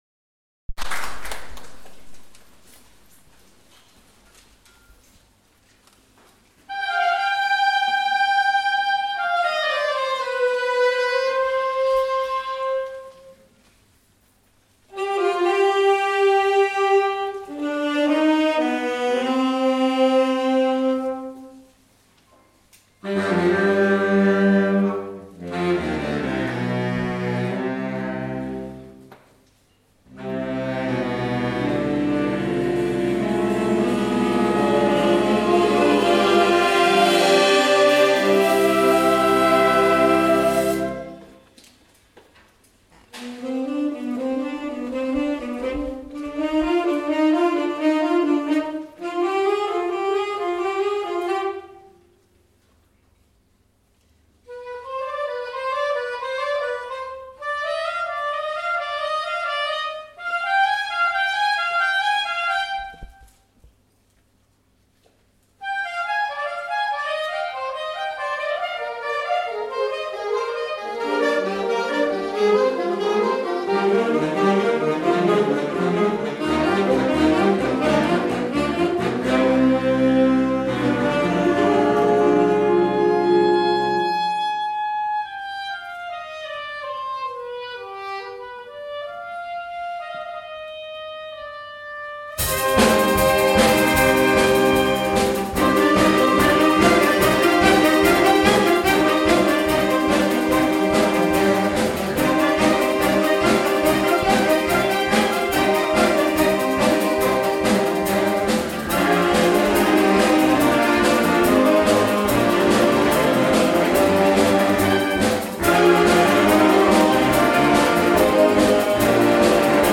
A Concert of Wind, Brass and Percussion, April 2015